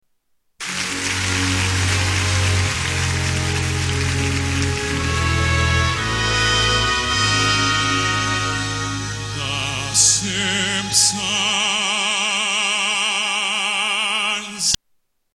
TV Theme Songs